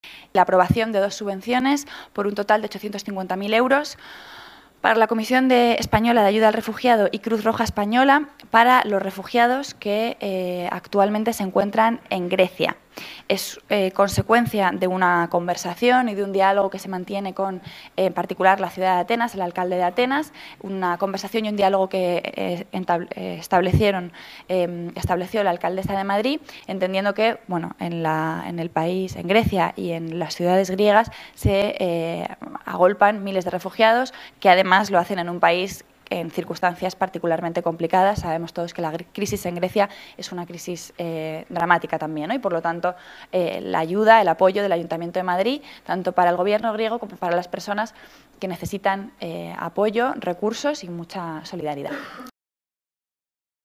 Nueva ventana:Rita Maestre explica el motivo de las subvenciones a CEAR y Cruz Roja